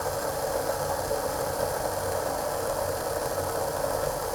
cooking_constant_01_loop.wav